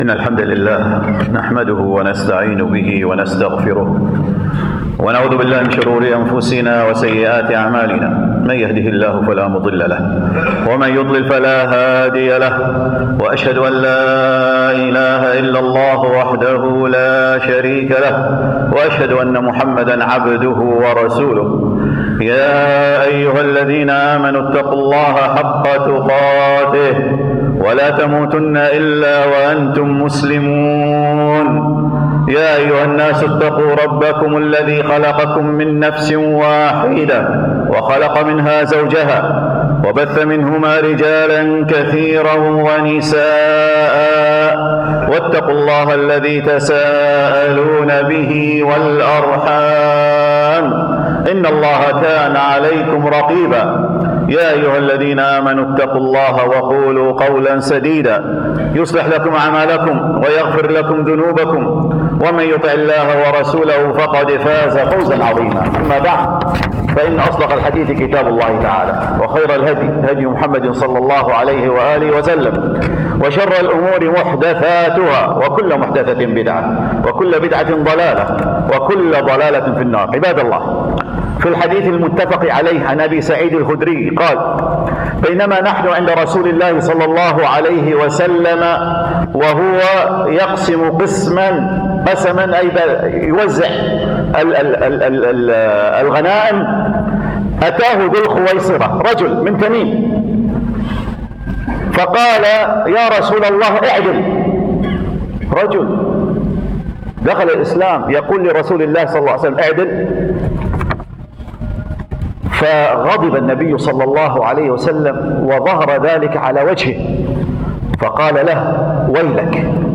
خطبة الجمعة بتاريخ 27 مارس